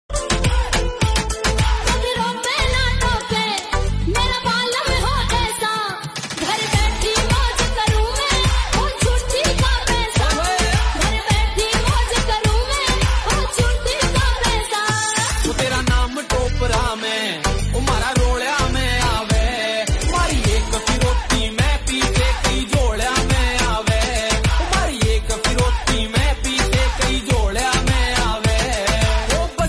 Haryanvi Songs